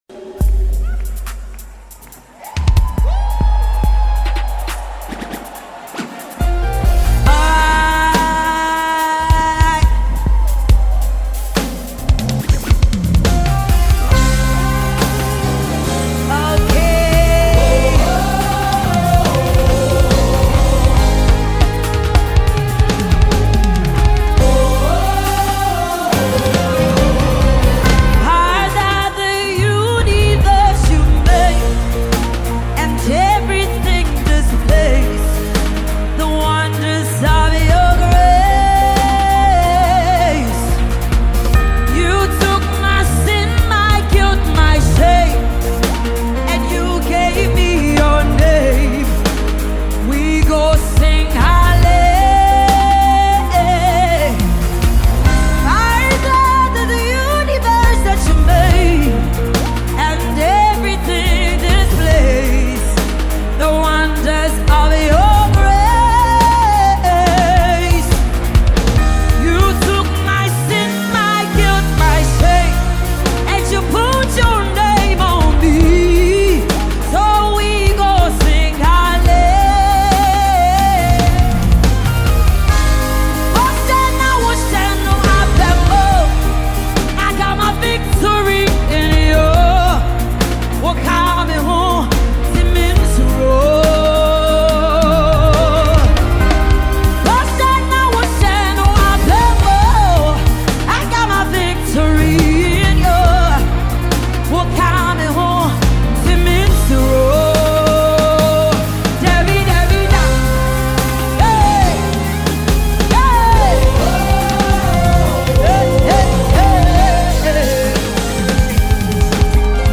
Gospel tune